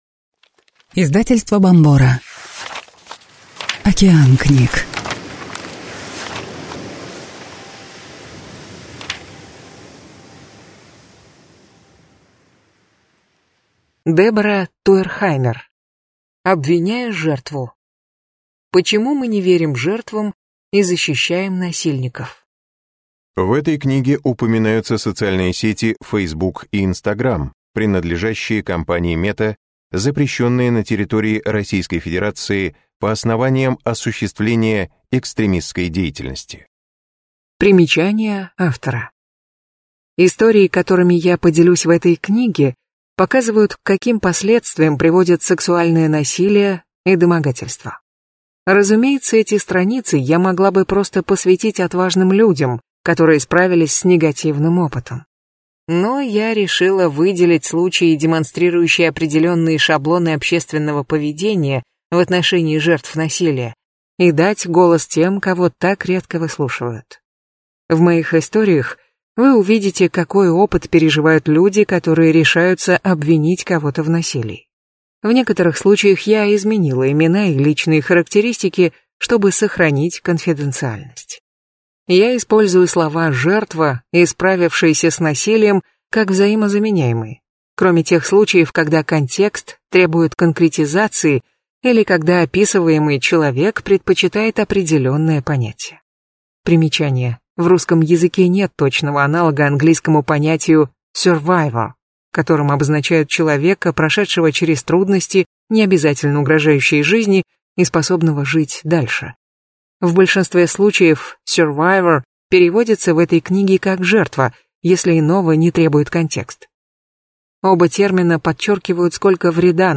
Аудиокнига Обвиняя жертву. Почему мы не верим жертвам и защищаем насильников | Библиотека аудиокниг